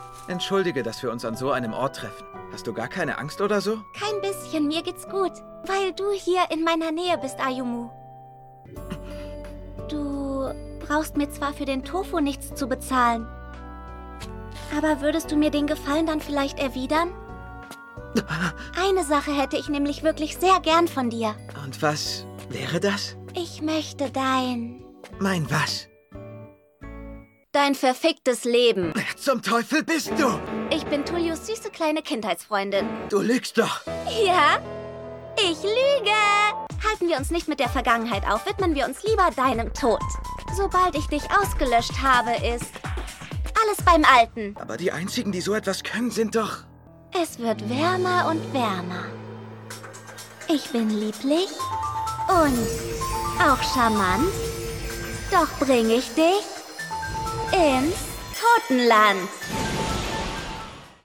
Sprecherin mit jugendlicher Stimme & Schauspielausbildung
Kein Dialekt
Sprechprobe: Sonstiges (Muttersprache):
Talent with young voice and acting education
Anime.mp3